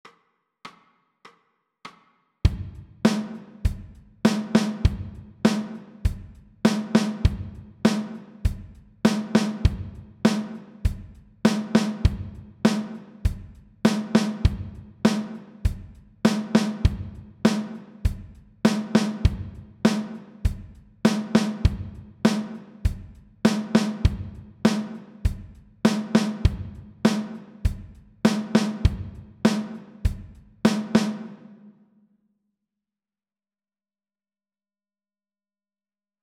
TRANSCRIBING DRUM GROOVES
You will hear a 4 beat intro followed by a one bar drum groove repeated 12 times.